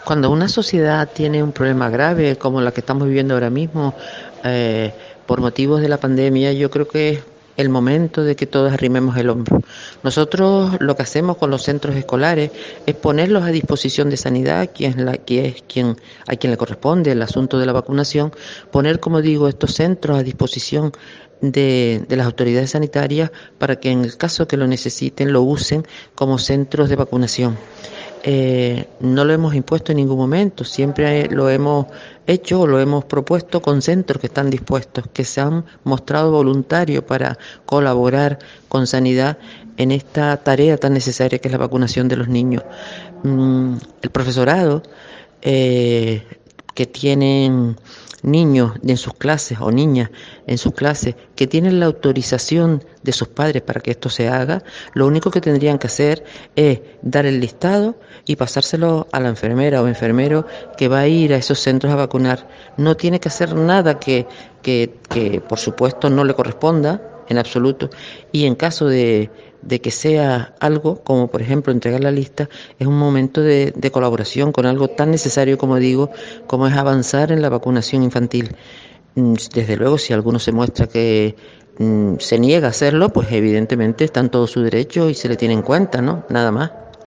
Manuela Armas, consejera de Educación del Gobierno de Canarias